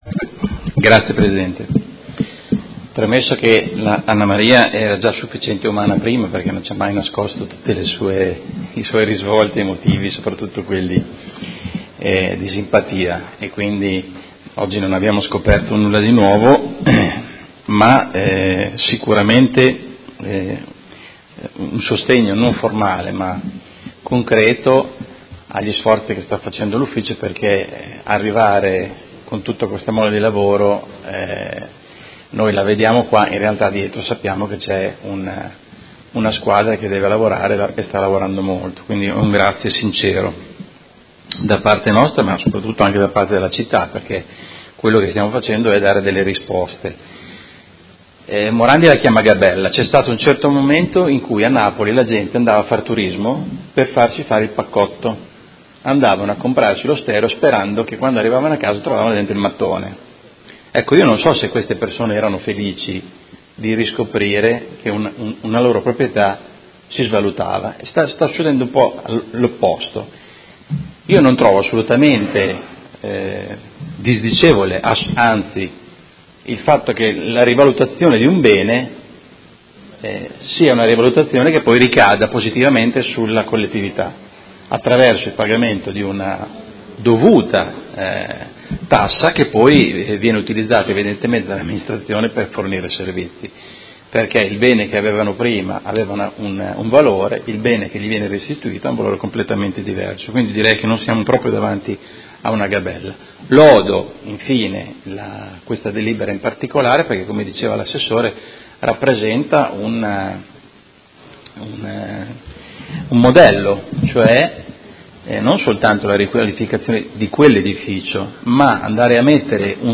Carmelo De Lillo — Sito Audio Consiglio Comunale
Seduta del 13/07/2017 Dibattito.